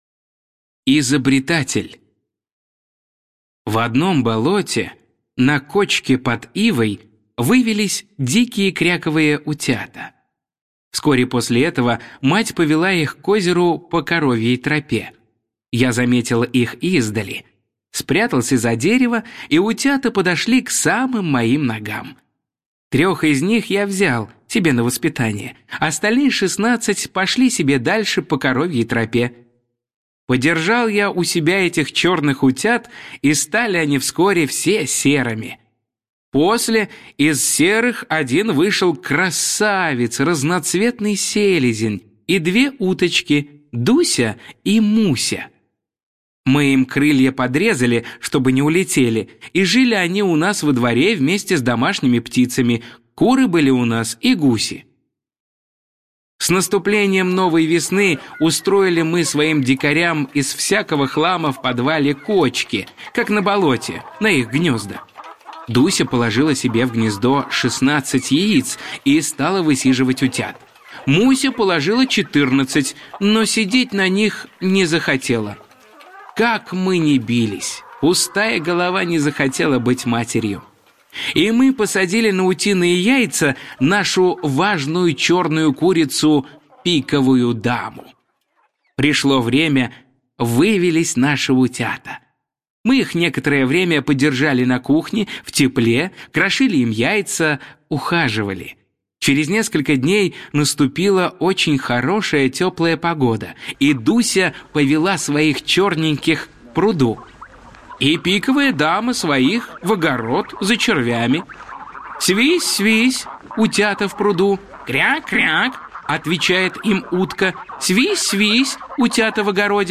«Изобретатель» – Пришвин М.М. (аудиоверсия)